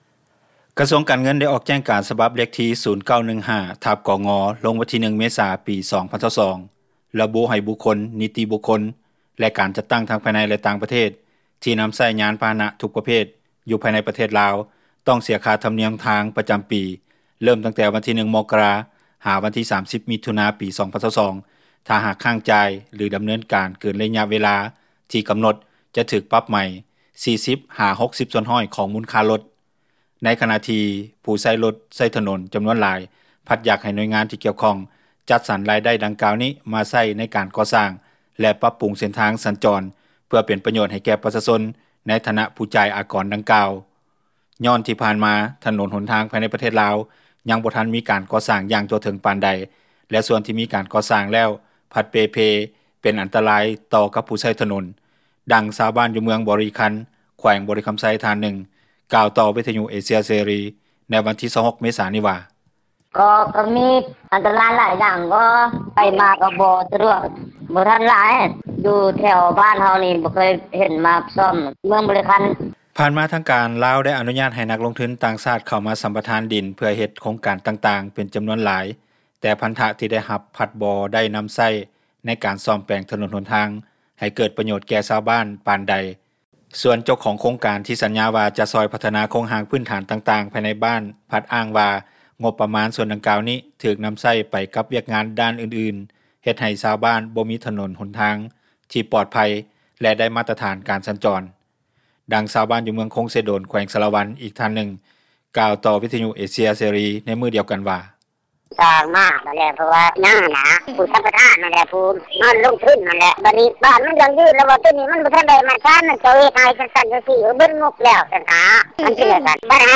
ນັກຂ່າວ ພົລເມືອງ
ດັ່ງຊາວບ້ານ ຢູ່ເມືອງບໍຣິຄັນ ແຂວງບໍຣິຄຳໄຊ ທ່ານນຶ່ງກ່າວຕໍ່ວິທຍຸເອເຊັຽເສຣີ ໃນວັນທີ 26 ເມສາ 2022 ນີ້ວ່າ:
ດັ່ງຊາວບ້ານ ຢູ່ເມືອງຄົງເຊໂດນ ແຂວງສາຣະວັນ ອີກທ່ານນຶ່ງກ່າວຕໍ່ວິທຍຸເອເຊັຽເສຣີ ໃນມື້ດຽວກັນນີ້ວ່າ: